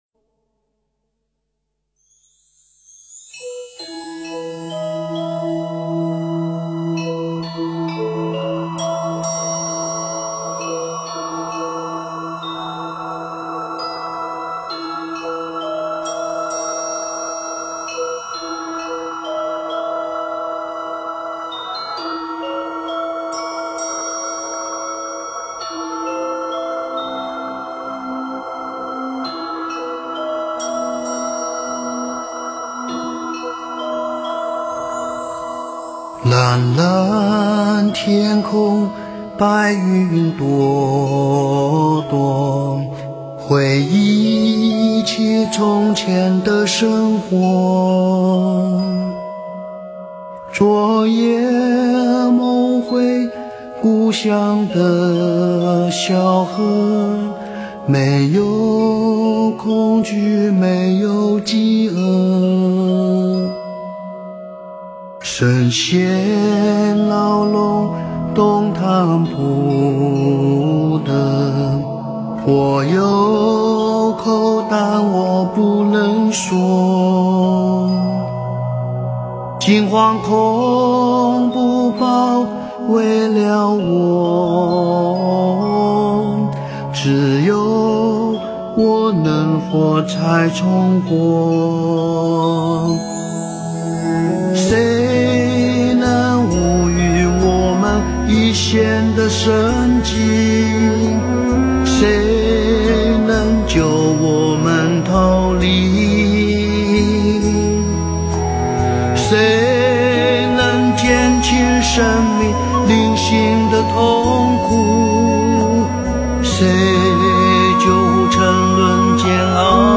诵经